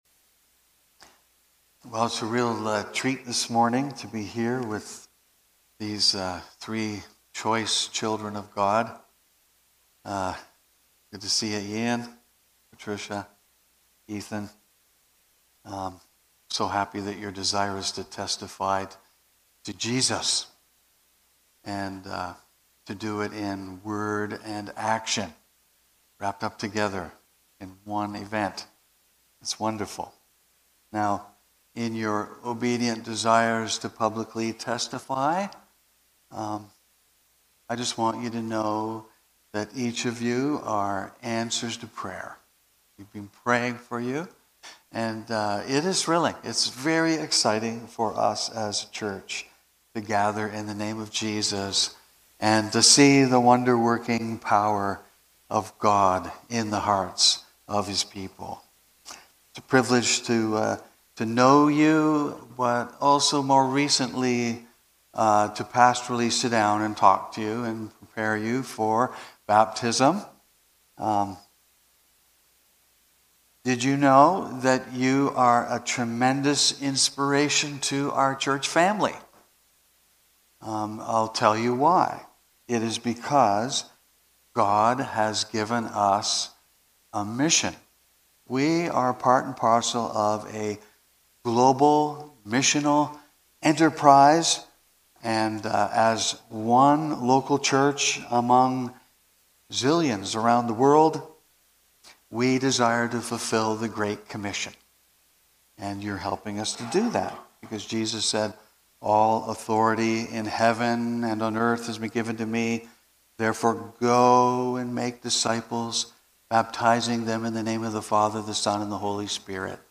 Baptism Sunday 2025 (1 of 2)